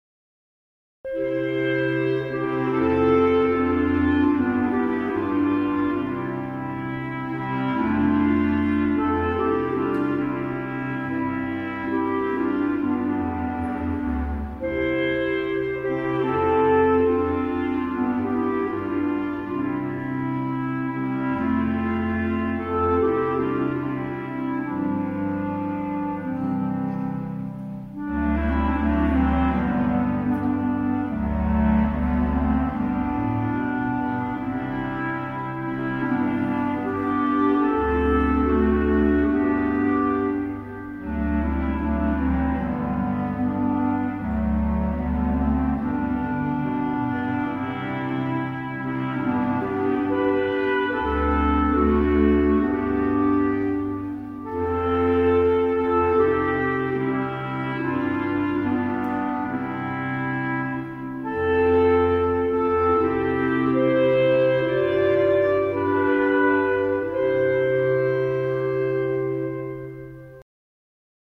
Wallfahrtsmesse in Admont
Aufgrund der schwierigen Wetterbedingungen (u.a. Schneefall) mussten wir aber die Messe alleine gestalten.